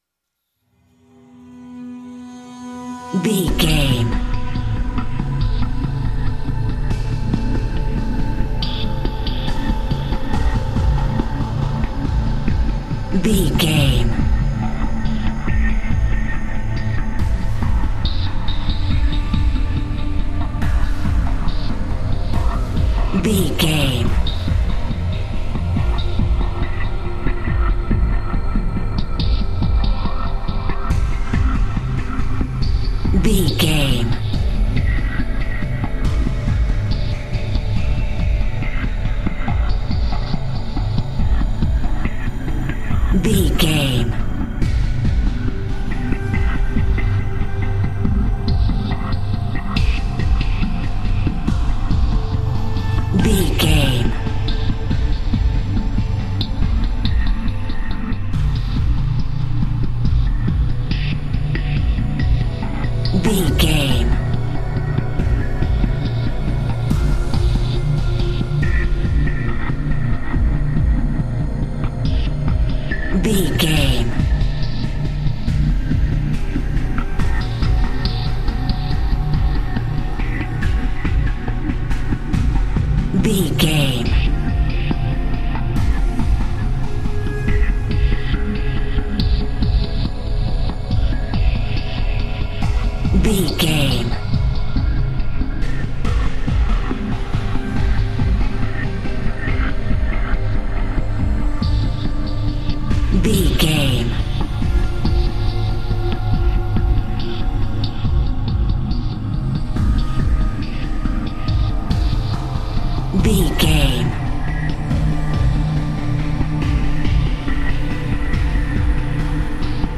Survival horror
Ionian/Major
synthesiser
drum machine